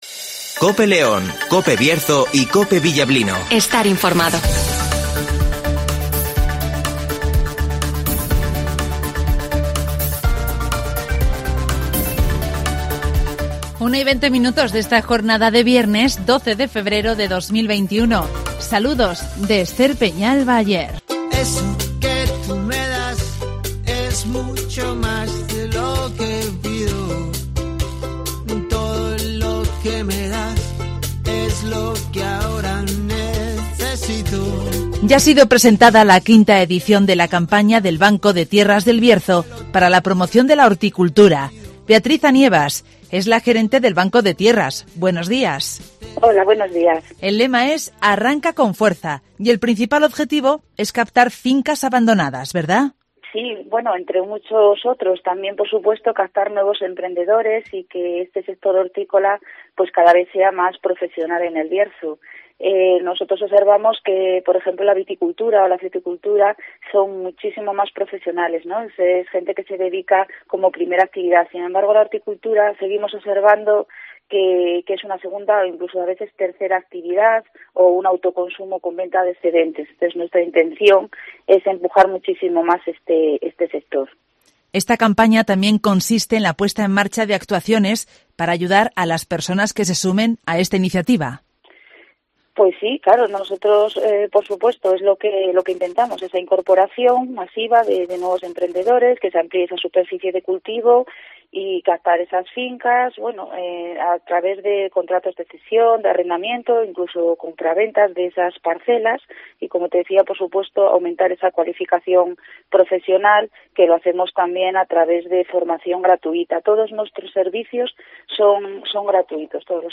En marcha la quinta edición de la campaña del Banco de Tierras del Bierzo para la promoción de la horticultura (Entrevista